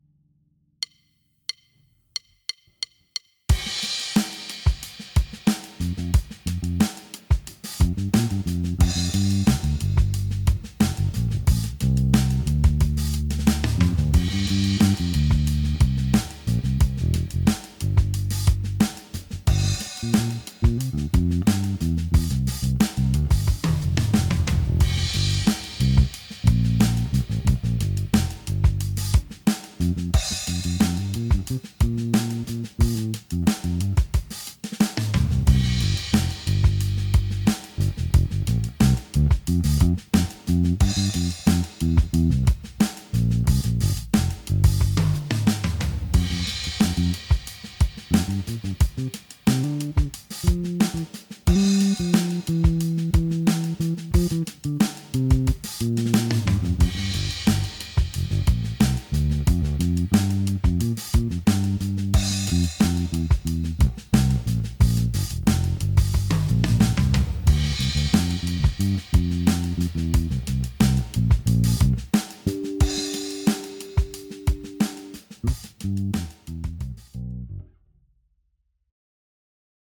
Jedná se Precision z dílny custom shopu v úpravě heavy relic.
Je víc konkrétní, má trochu více výšek, ale zároveň má pevný základ palisandru a jeho kulatost.
Zvukově naprosté dělo, ačkoliv díky hlazenkám není 100% univerzální, jak uslyšíte z nahrávek.